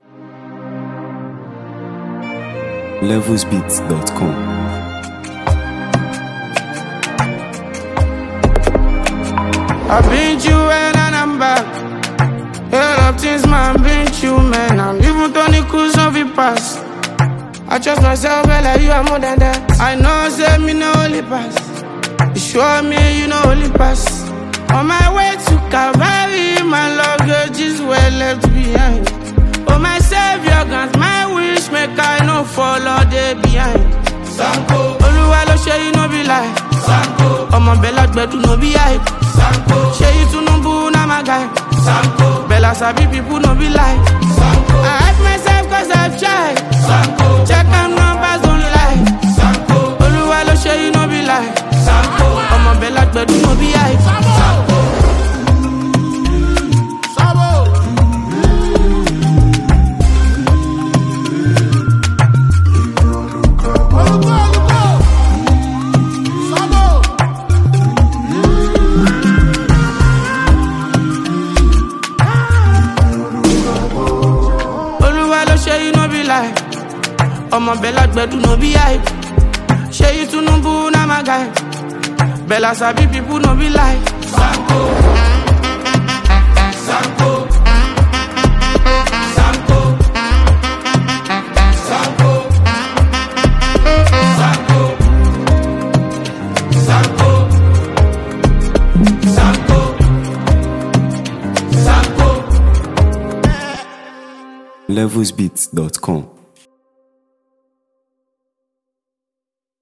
Highly talented Nigerian music sensation and street-pop star